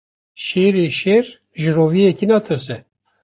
Pronúnciase como (IPA) /ʃeːɾ/